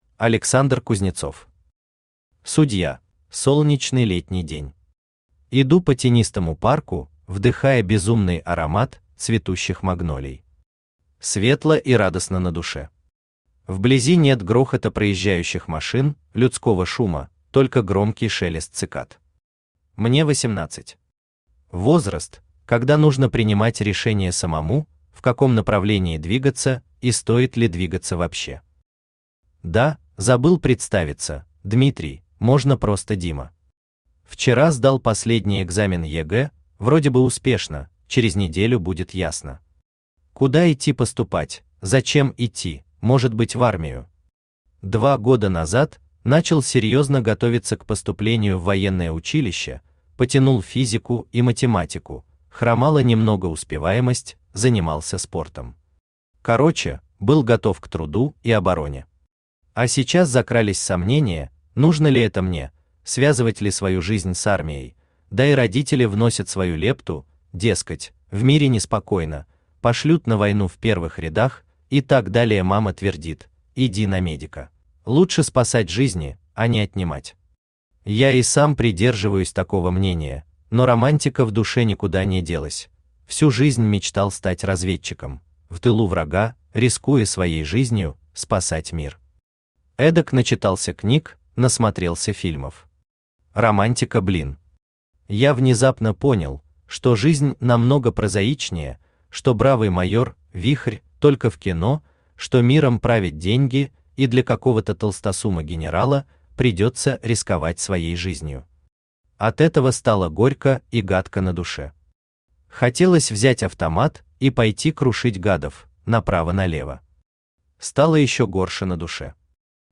Аудиокнига Судья | Библиотека аудиокниг
Aудиокнига Судья Автор Александр Евгеньевич Кузнецов Читает аудиокнигу Авточтец ЛитРес.